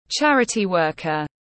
Charity worker /ˈtʃær.ə.ti ˈwɜː.kər/